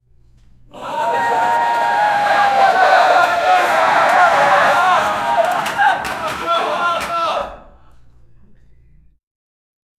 Crowd Murmur Low
crowd-murmur-low-bwuuvqcp.wav